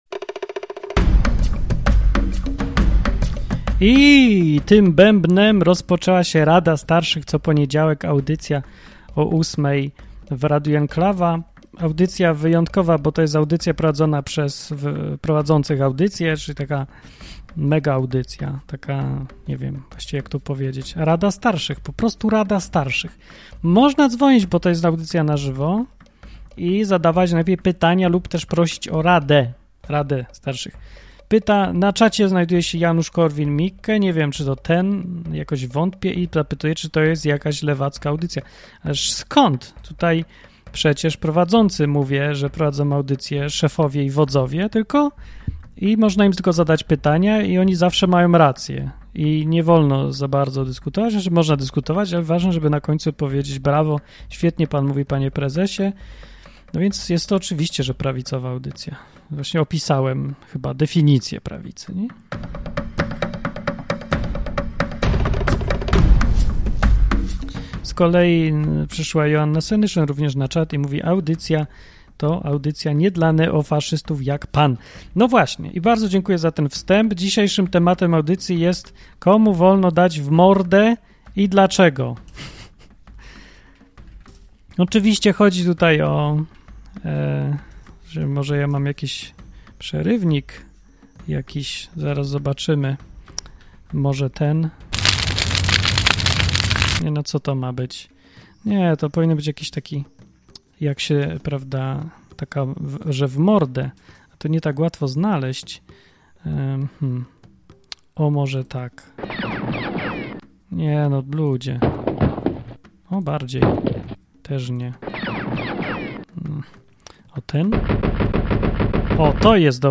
Co tydzień w poniedziałek prowadzący programy w Enklawie zbierają się, aby udzielać słuchaczom rad.